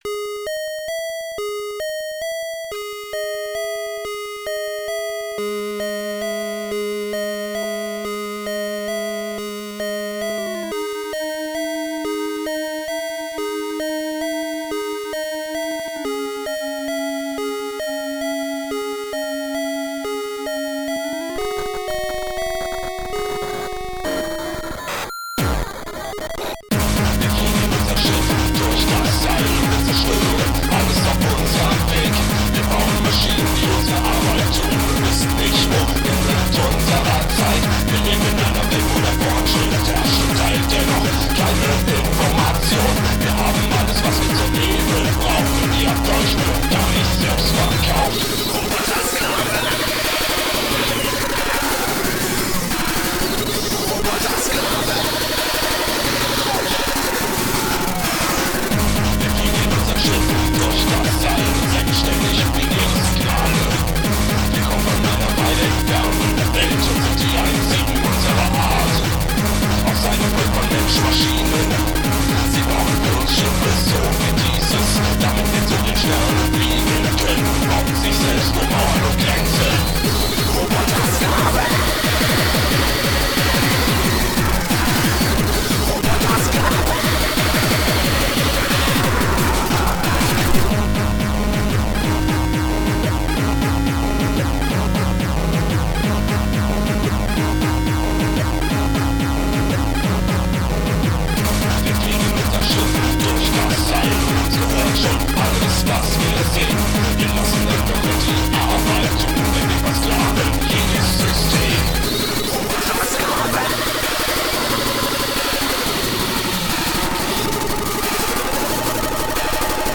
Song composed on a Nintendo GameBoy (DMG) running LSDJ 3.92
Vocals recorded on a Boss BR-1600